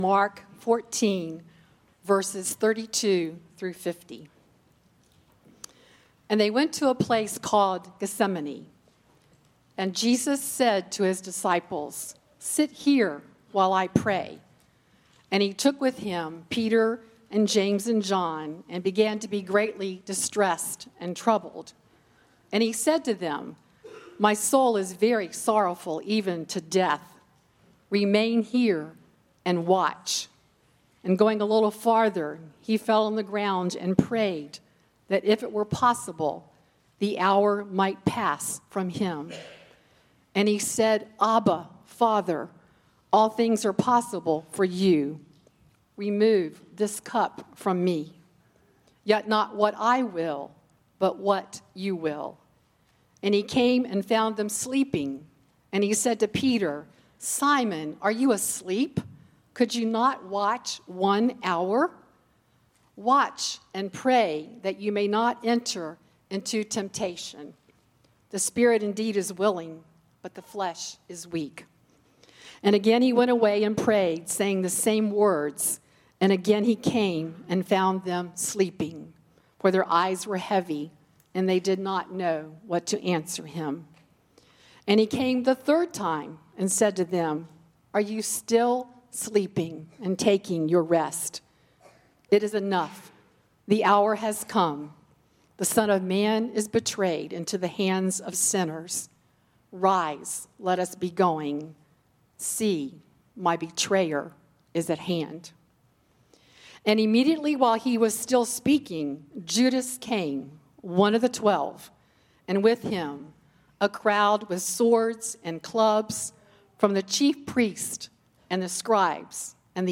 The King & His Cross Current Sermon